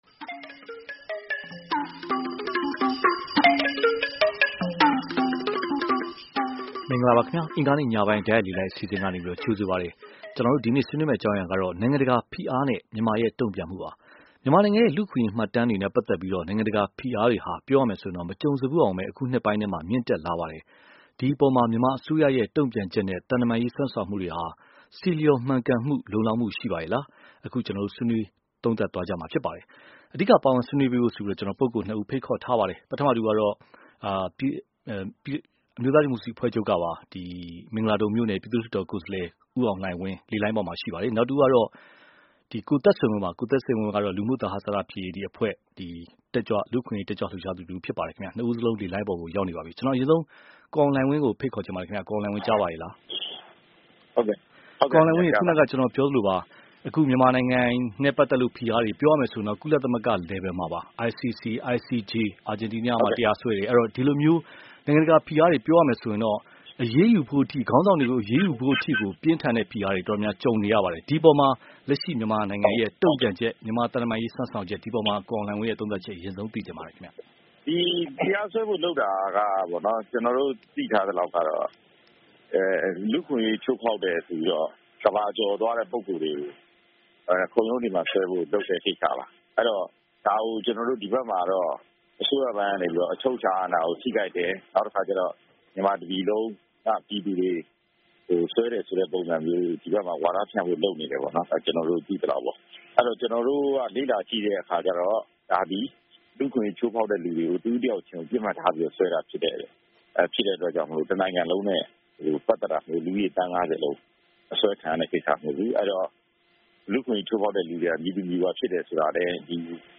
နိုင်ငံတကာဖိအားနဲ့ မြန်မာတုန့်ပြန်မှု(တိုက်ရိုက်လေလှိုင်း)